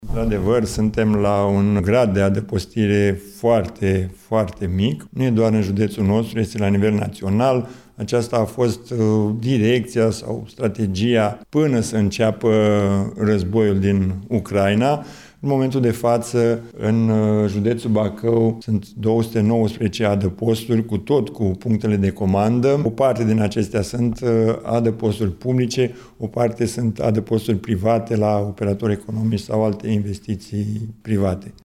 În contextul actual, evaluările periodice și investițiile constante sunt necesare pentru a menține aceste spații funcționale și sigure, după cum a precizat colonelul Ovidiu Cărășel, inspector șef al ISU Bacău.